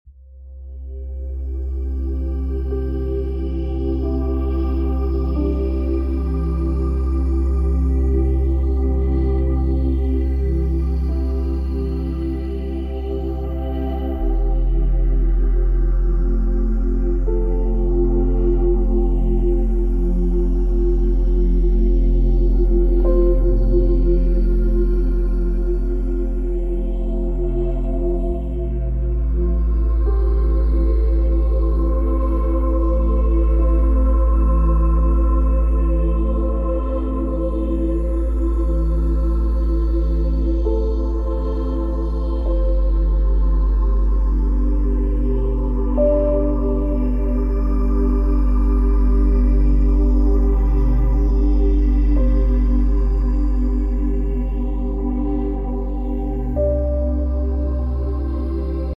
8D audio waves help alleviate sound effects free download
8D audio waves help alleviate tension in the body, relieve headaches, and promote relaxation and calmness. Use headphones to fully experience this spatial sound.